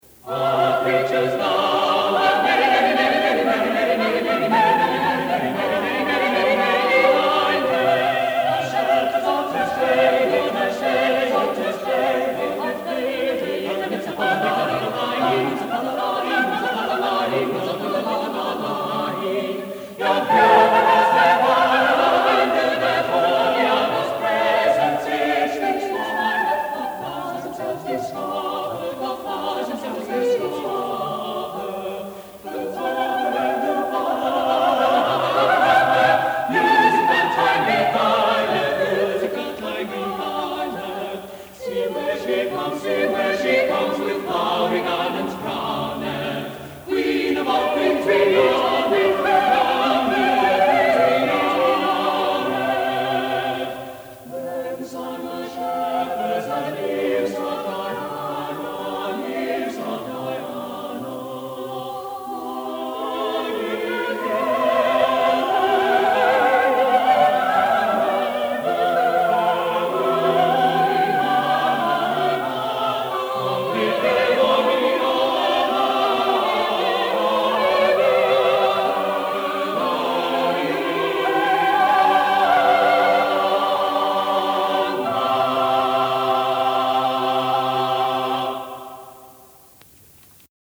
| Vocal ensemble, 'In Praise of Women' 1976